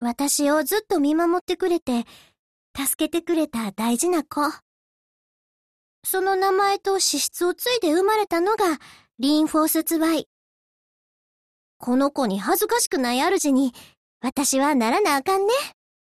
文件 126 KB =={{int:filedesc}}== 游戏语音 =={{int:license-header}}== {{fairuse}} 1